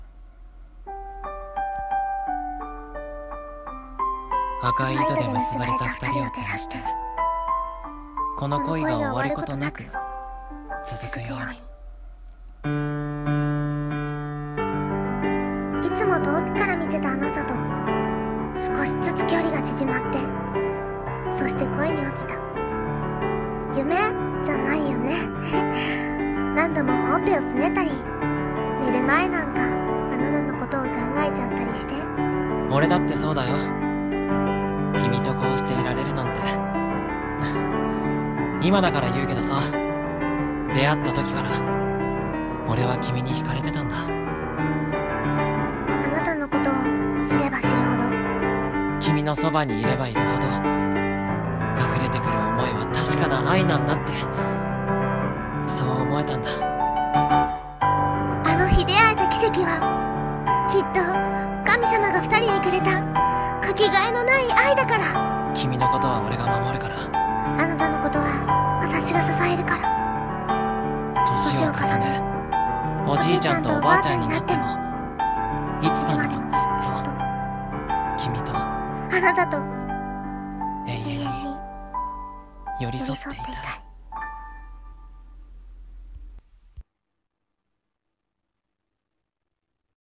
コラボ声劇『赤い糸』